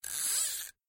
Звуки удочки
Вытягиваем леску из катушки пальцами